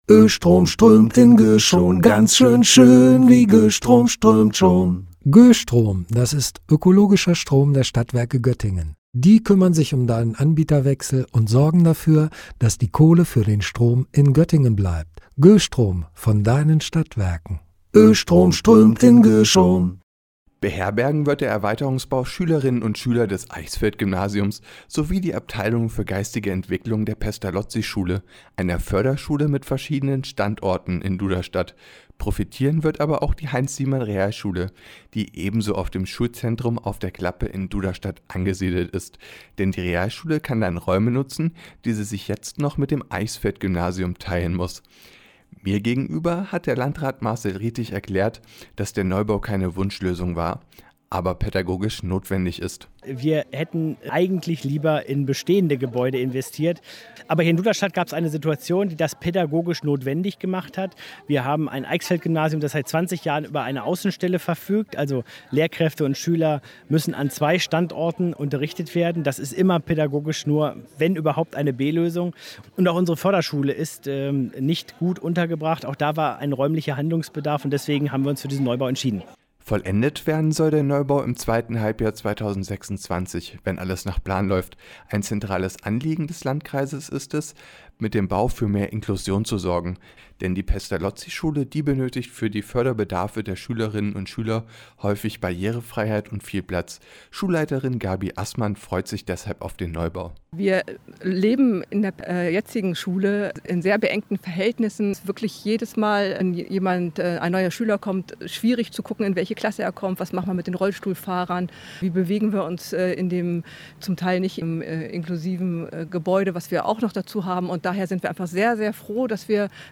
Der Landkreis Göttingen erweitert das Schulzentrum Auf der Klappe in Duderstadt. Insgesamt rund 38 Millionen Euro soll der Erweiterungsbau kosten. Gerade rollen die ersten Baumaschinen an.